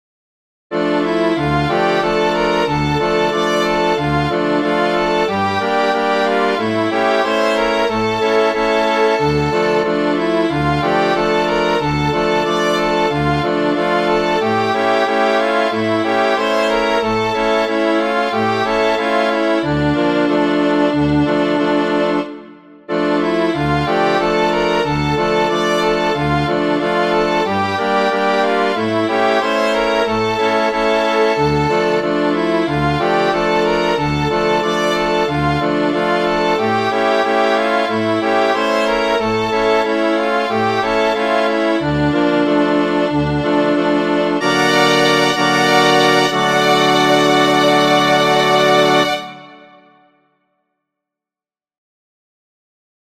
– Contos tradicionais musicados e dramatizados.